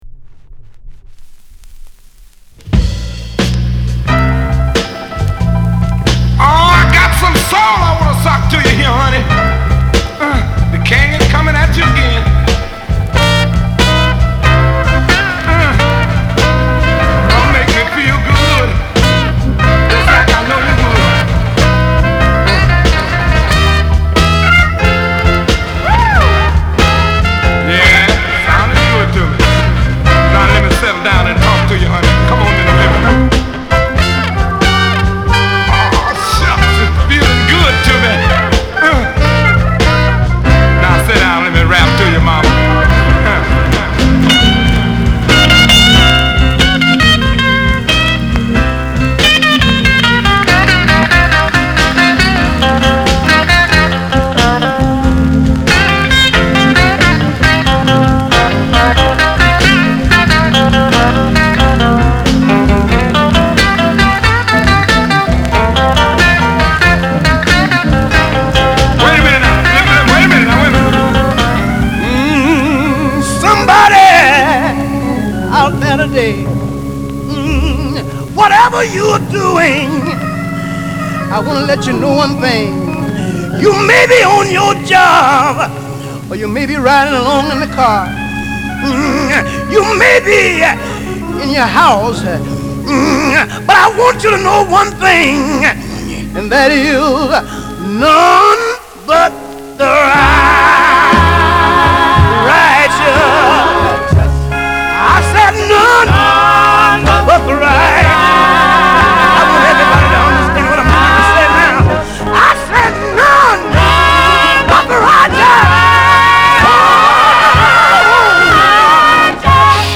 類別 藍調